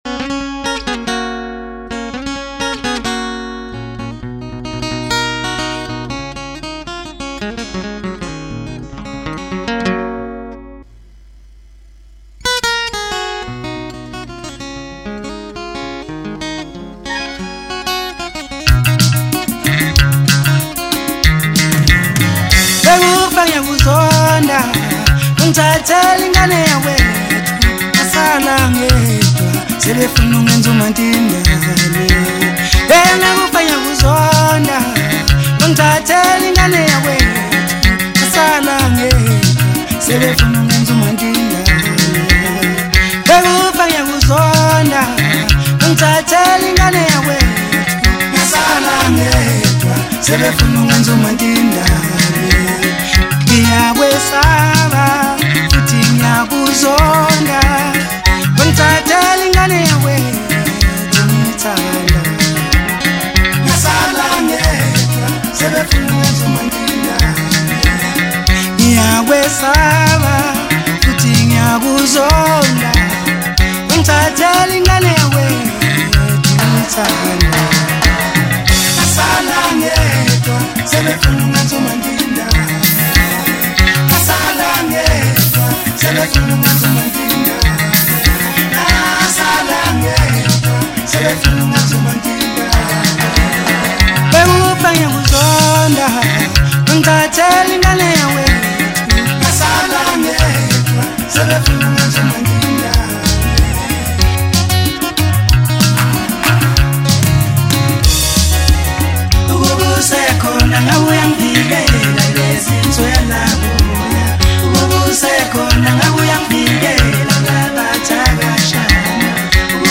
MASKANDI MUSIC